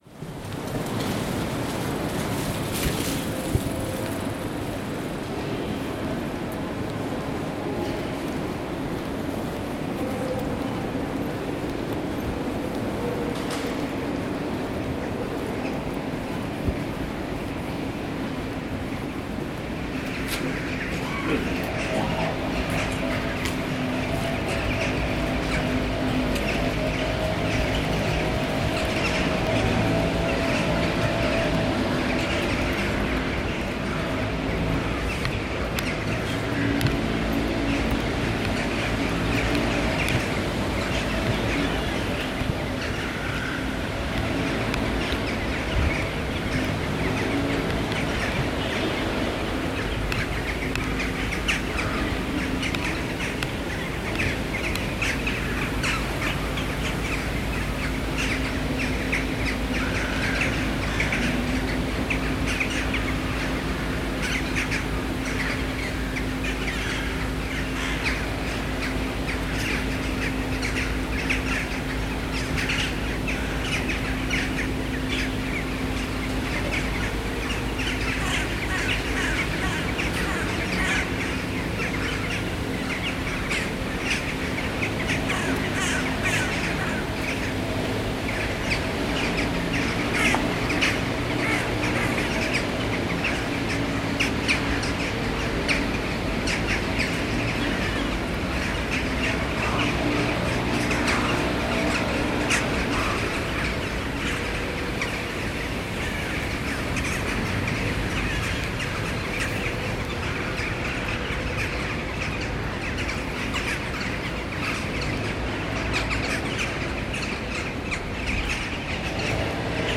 Vor dem Bahnhof eine Tonaufnahme von Dohlen, Krähen, Kehrmaschine gemacht.
Die Vögel sammeln sich hier an jedem Morgen vor der Dämmerung. Sie sitzen in den Baumwipfeln und fliegen in kleinen Gruppen über dem Bahnhofsvorplatz, über dem ein Netz aus Kabeln gespannt ist, an welchen Lampen hängen.